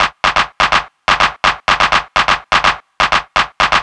cch_percussion_loop_night_125.wav